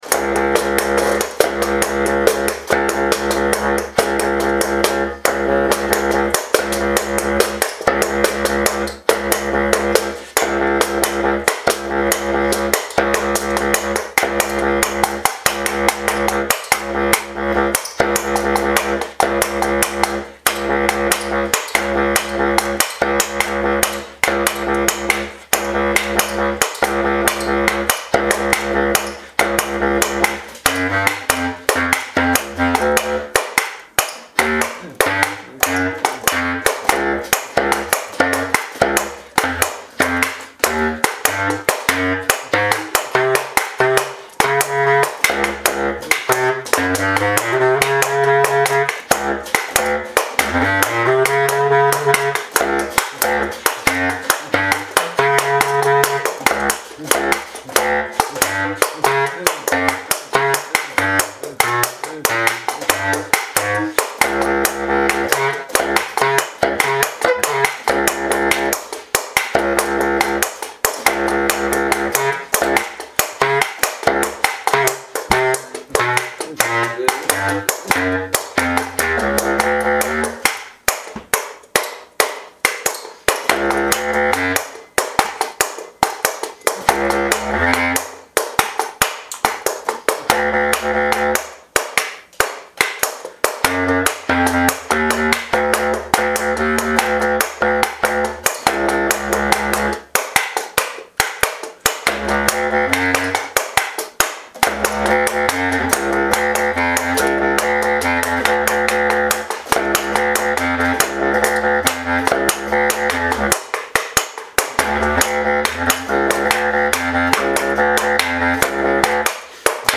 Recorded live
kitchen, NYC.
bass clarinet
washboard
Stereo (Olympus portable recorder)